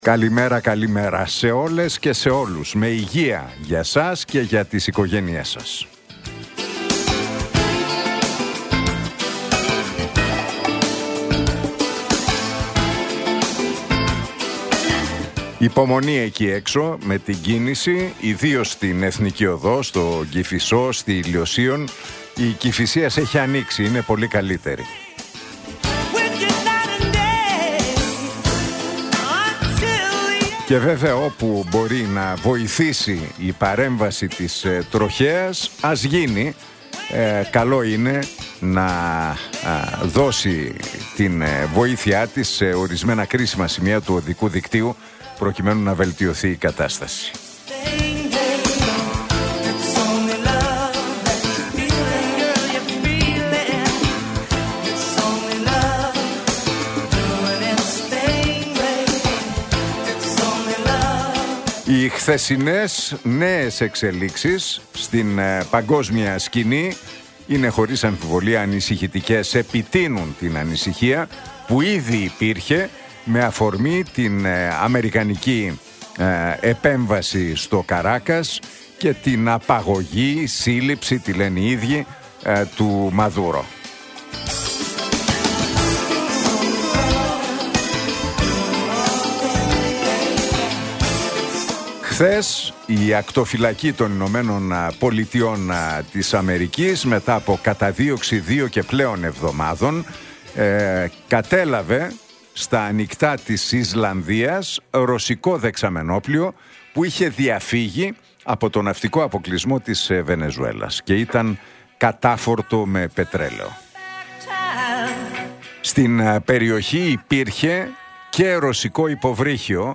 Ακούστε το σχόλιο του Νίκου Χατζηνικολάου στον ραδιοφωνικό σταθμό Realfm 97,8, την Πέμπτη 8 Ιανουαρίου 2026.